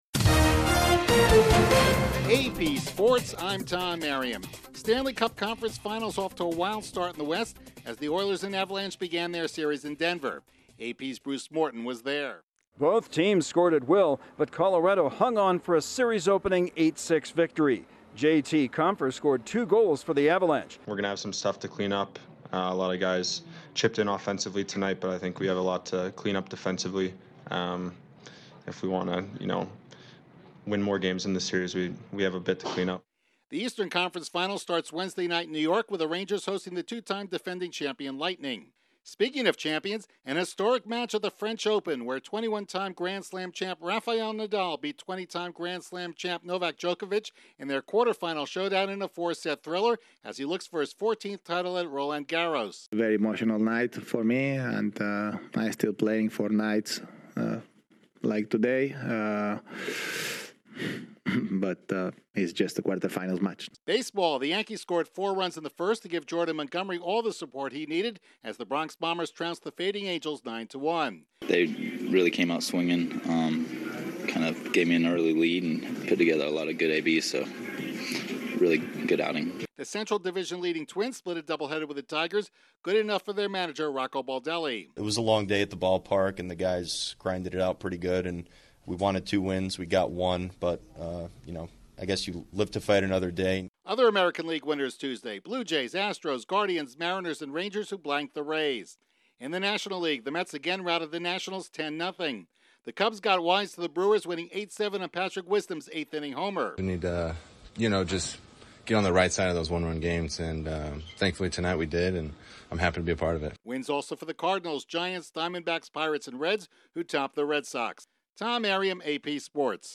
The Avalanche outscore the Oilers to start the NHL Western Conference Final, Rafael Nadal beats Novak Djokovic in an historic French Open quarterfinal, the Yankees score early and often and the Cubs win with Wisdom. Correspondent